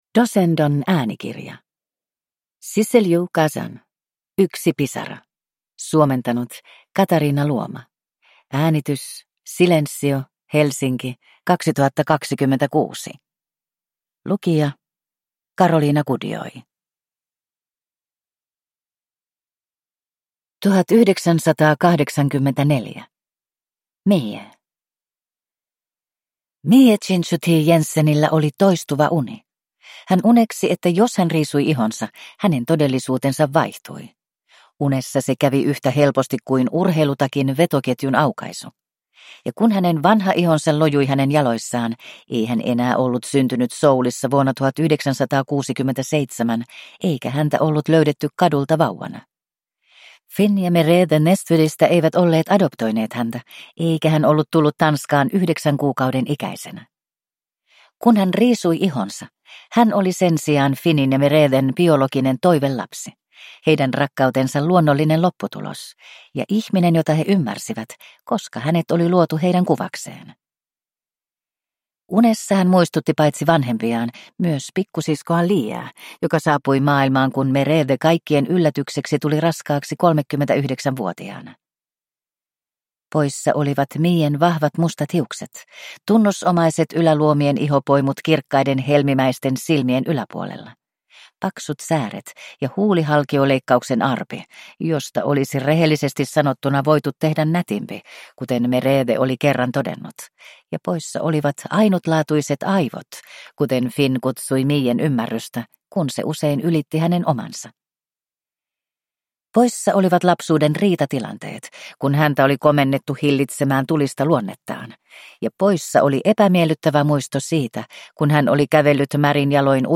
Yksi pisara (ljudbok) av Sissel-Jo Gazan